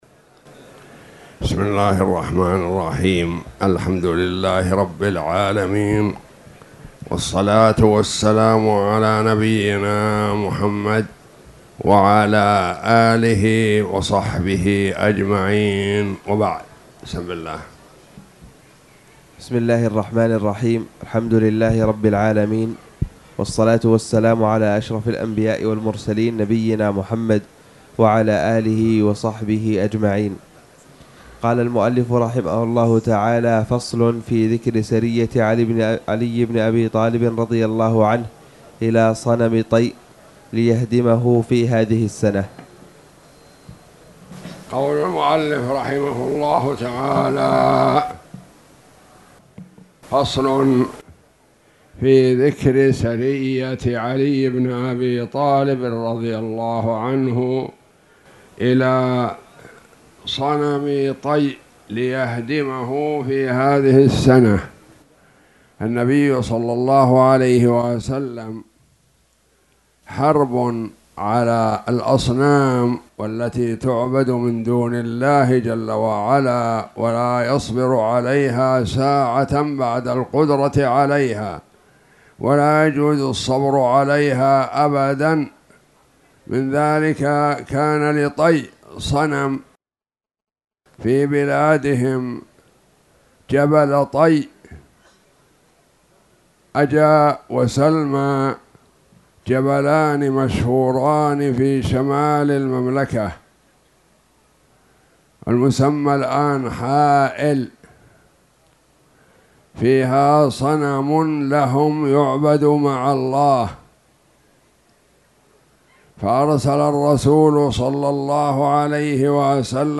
تاريخ النشر ١٩ رمضان ١٤٣٧ هـ المكان: المسجد الحرام الشيخ